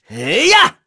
Neraxis-Vox_Attack4.wav